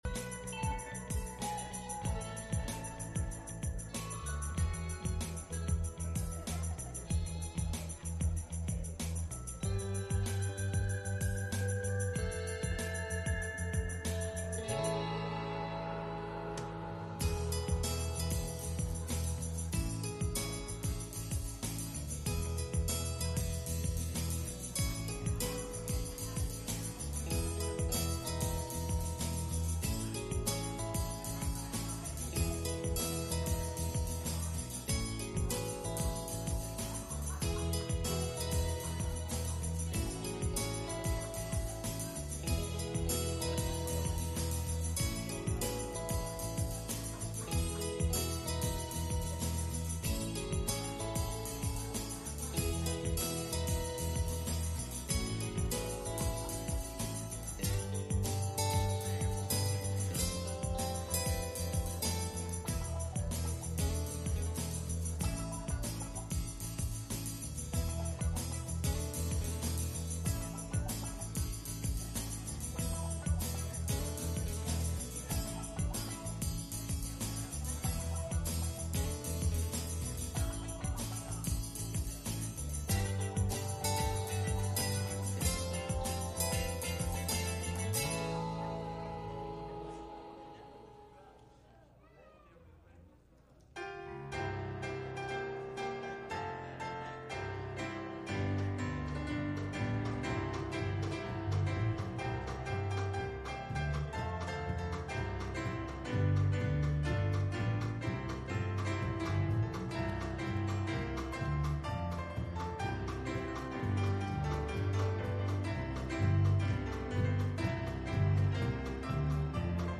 Matthew 4:18-20 Service Type: Sunday Morning « The Floating Axe-head